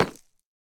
Minecraft Version Minecraft Version 25w18a Latest Release | Latest Snapshot 25w18a / assets / minecraft / sounds / block / deepslate_bricks / step3.ogg Compare With Compare With Latest Release | Latest Snapshot
step3.ogg